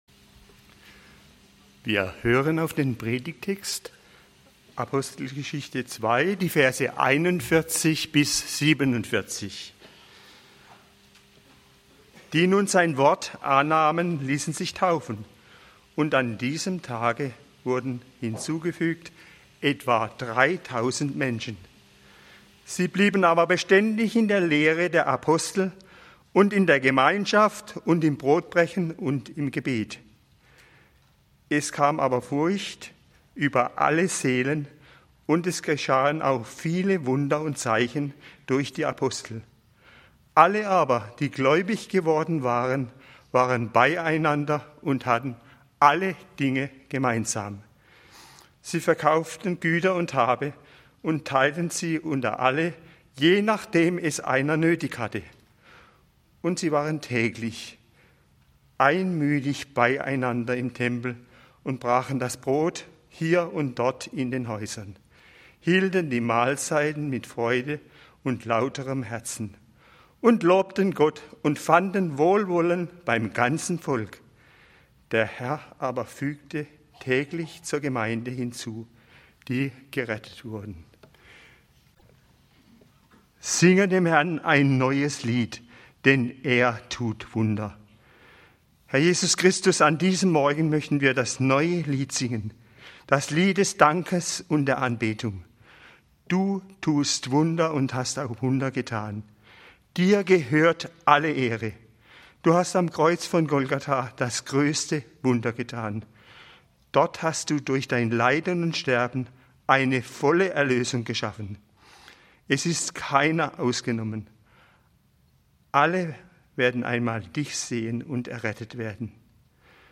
Gemeinde - ein Wunder! (Apg. 2, 41-47) - Gottesdienst